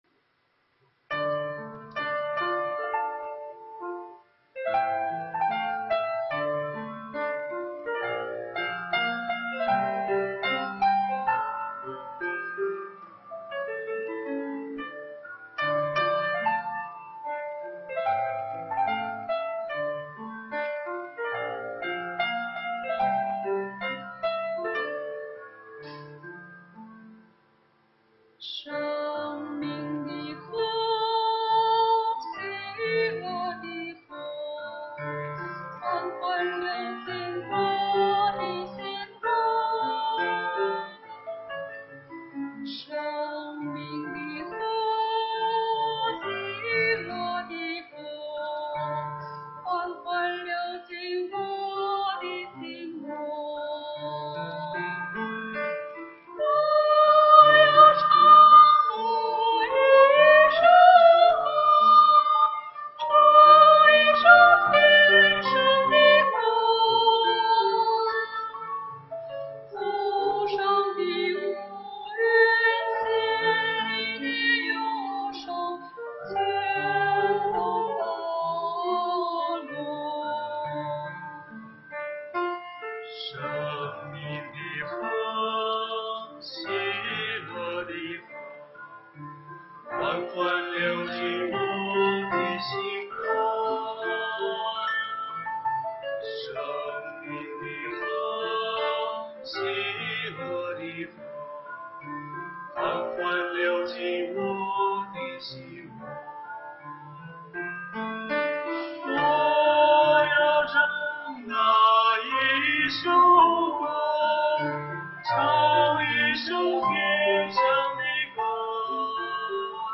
中秋赞美会：《天上的歌》 生命的河 喜乐的河 缓缓流进我的心窝 生命的河 喜乐的河 缓缓流进我的心窝 我要唱那一首歌 唱一首天上的歌 头上的乌云 心里的忧伤 全都洒落